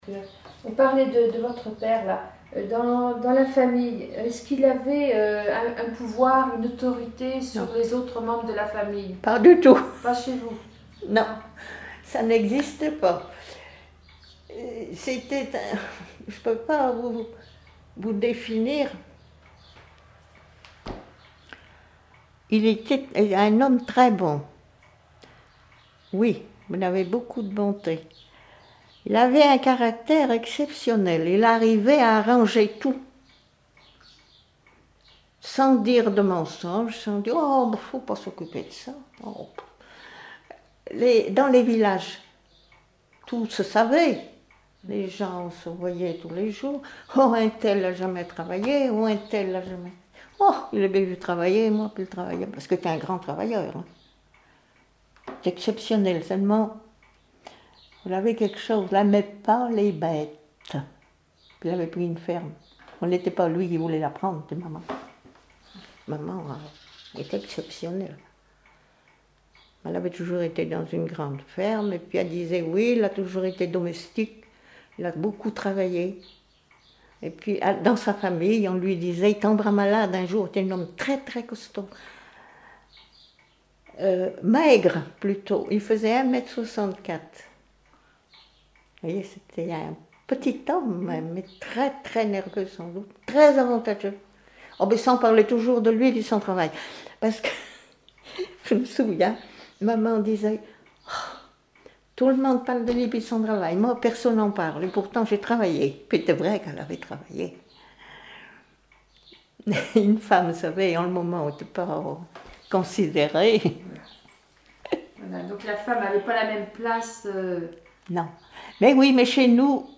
Témoignage sur la vie de l'interviewé(e)
Catégorie Témoignage